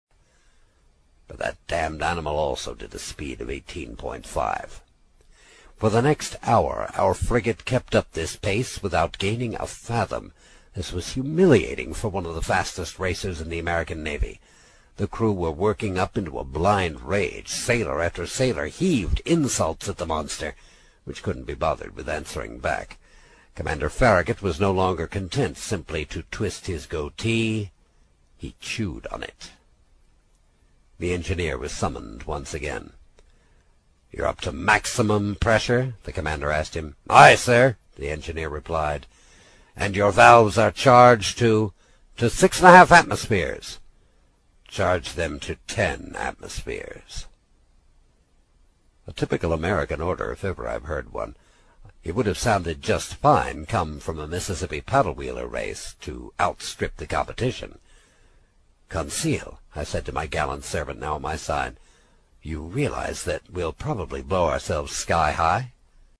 英语听书《海底两万里》第71期 第6章 开足马力(13) 听力文件下载—在线英语听力室
在线英语听力室英语听书《海底两万里》第71期 第6章 开足马力(13)的听力文件下载,《海底两万里》中英双语有声读物附MP3下载